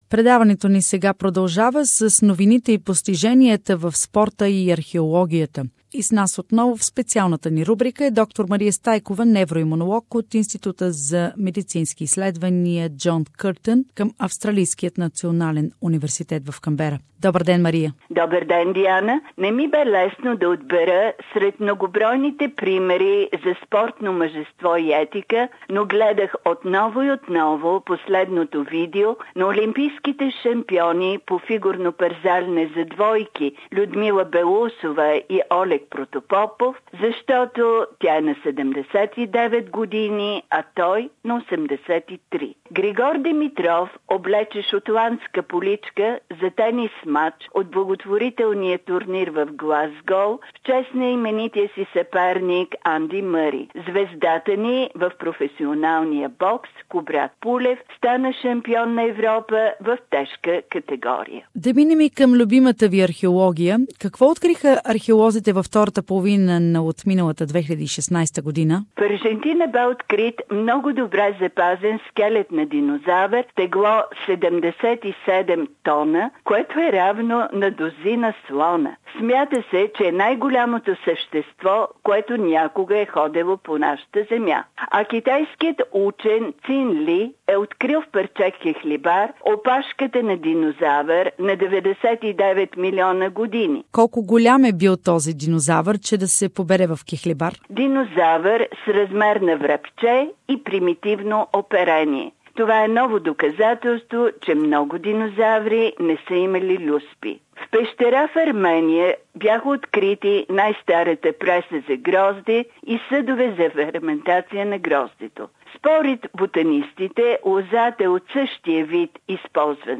What are the sport achievements and the archeology discoveries of the last half of 2016 - interview